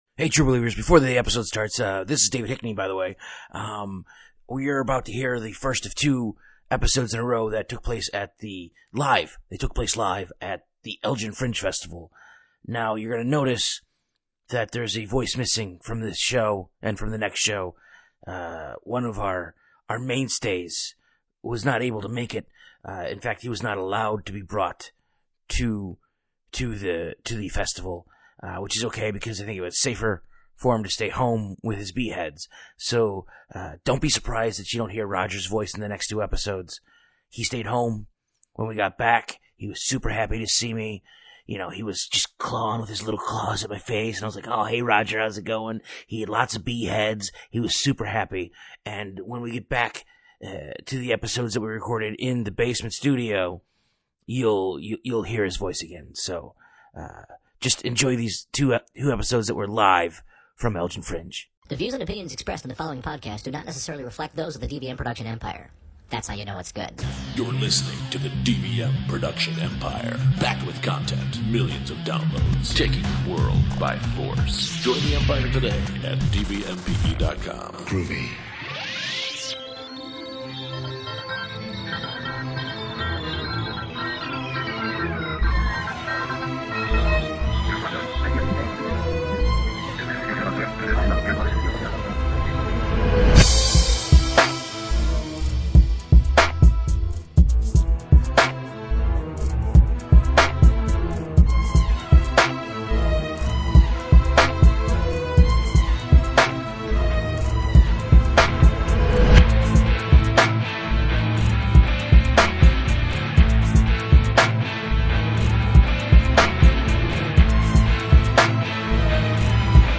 In this, the first episode recorded live at the Elgin Fringe Festival we discuss animal weapons.